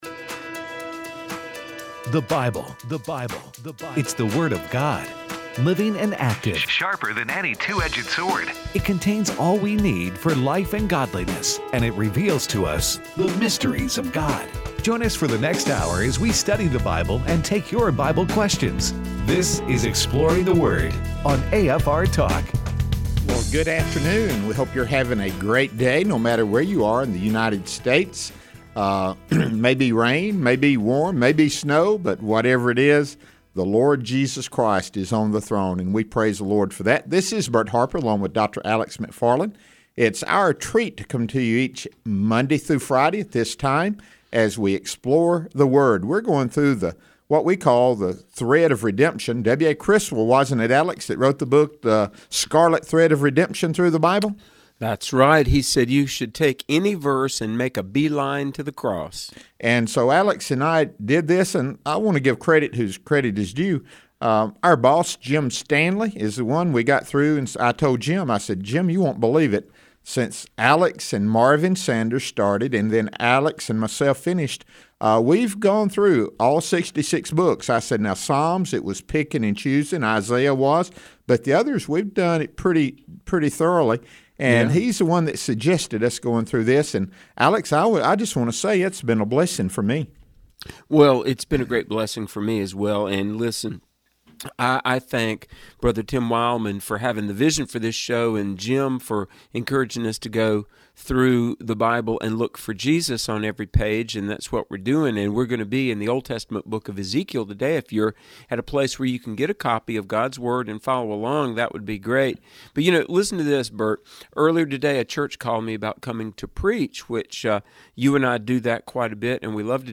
discuss the redemptive thread of Christ in the book of Ezekiel and then takes your phone calls.